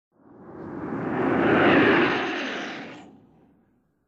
BSG FX - Viper - Pass by 02
BSG_FX_-_Viper_-_Pass_by_02.wav